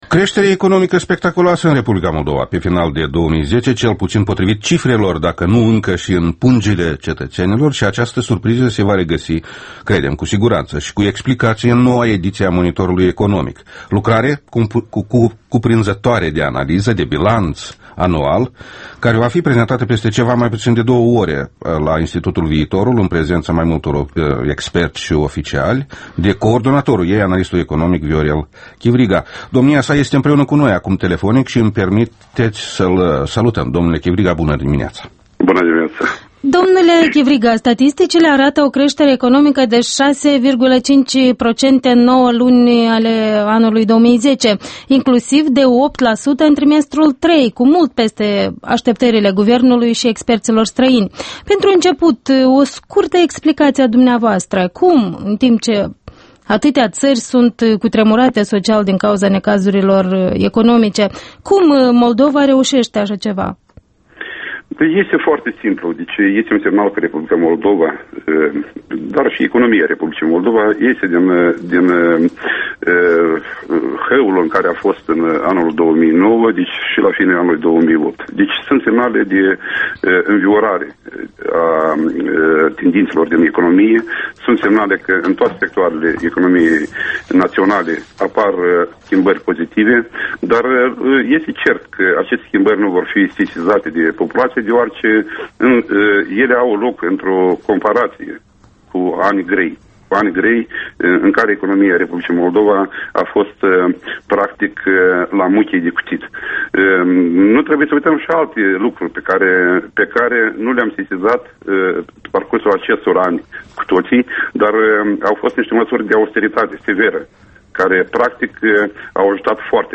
Interviul matinal EL